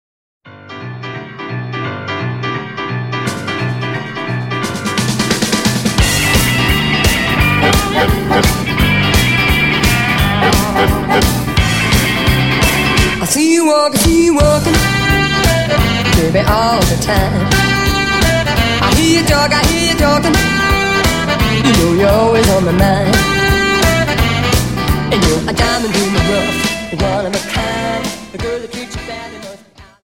Jive 43 Song